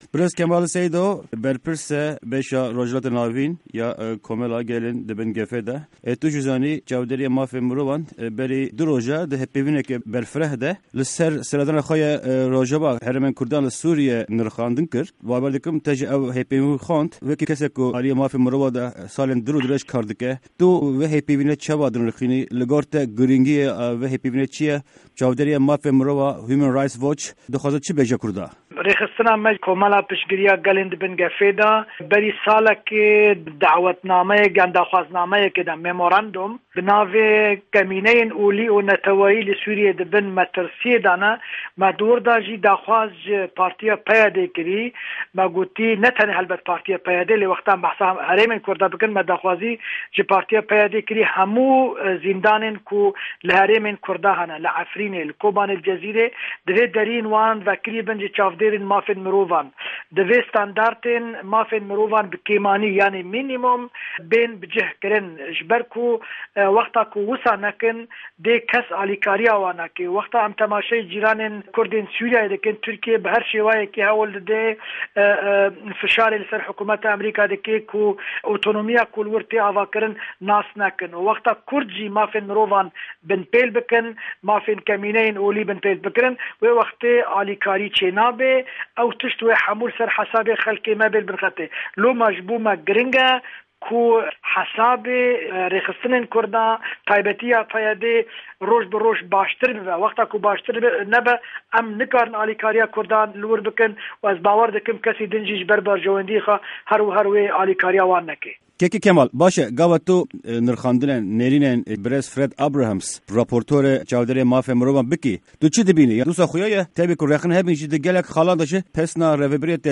Di hevpeyvîna Dengê Amerîka de